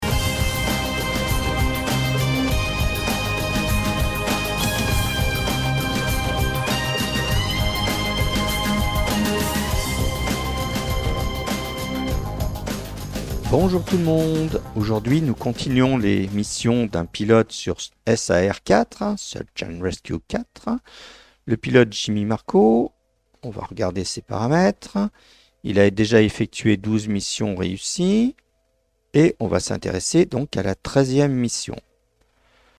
P'tit montage audio perso, pour voir ce que ça donnerait:
pour la voix.